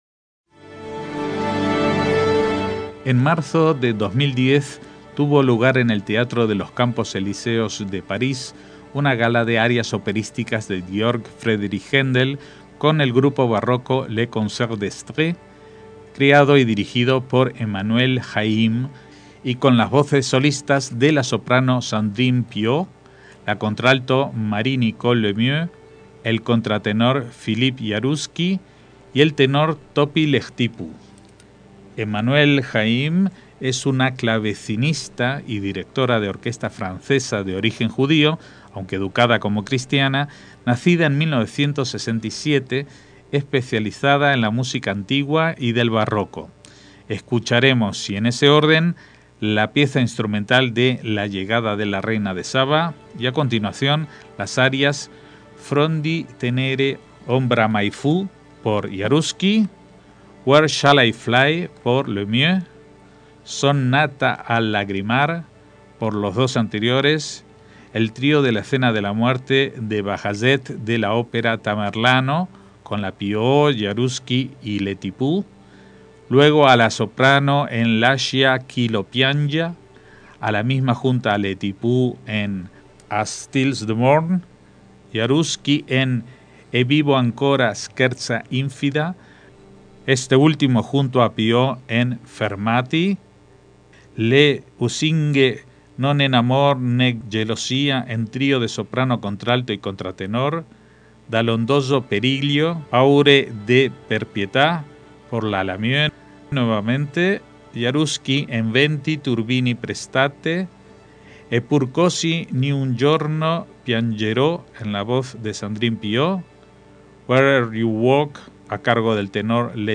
grupo barroco
soprano
contralto
contratenor
tenor
pieza instrumental